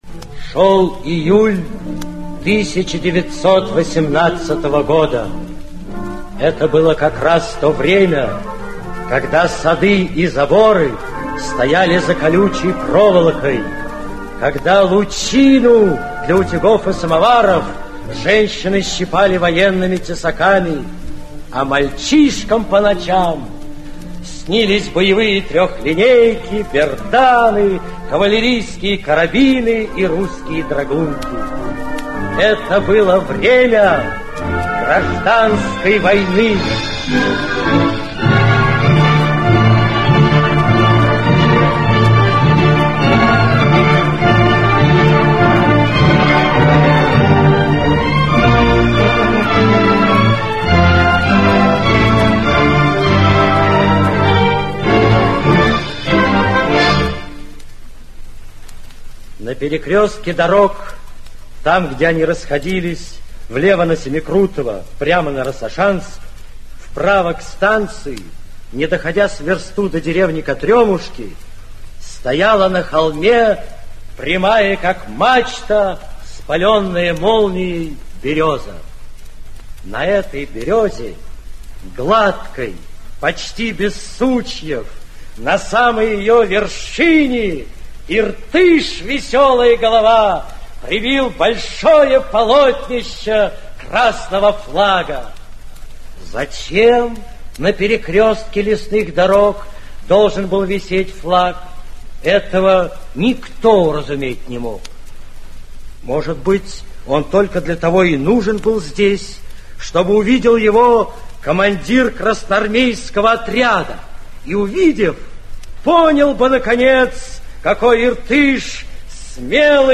Аудиокнига Бумбараш (спектакль) | Библиотека аудиокниг
Aудиокнига Бумбараш (спектакль) Автор Аркадий Гайдар Читает аудиокнигу Актерский коллектив.